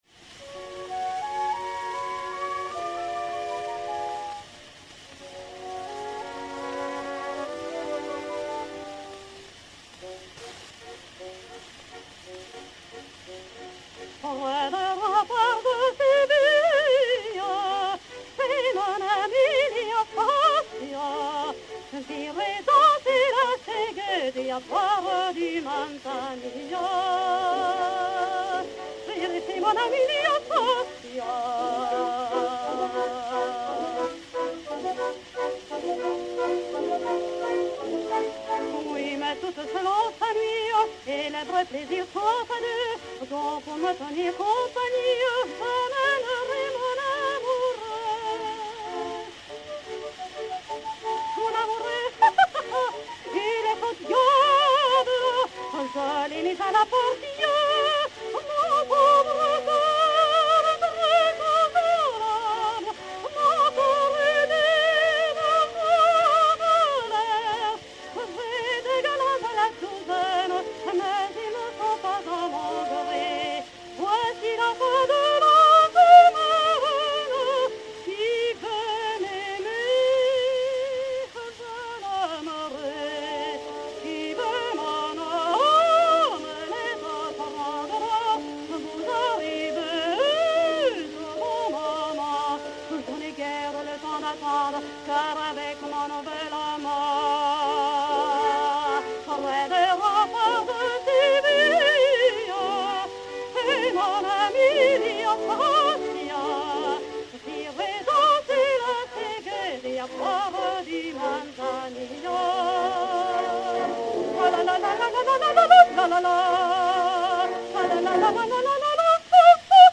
soprano suédois
La voix de Sigrid Arnoldson, très étendue mais non très puissante, est d'un timbre délicieux et d'une égalité prodigieuse. Sa vocalisation est d'une sûreté et d'une agilité remarquables.
Sigrid Arnoldson (Carmen) et Orchestre
Disque Pour Gramophone 33609, mat. 1285r, enr. à Berlin en juin 1906